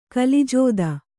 ♪ kalijōda